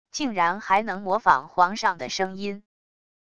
竟然还能模仿皇上的声音wav音频